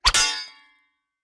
Tiếng 2 thanh Kiếm va chạm với nhau (1 tiếng)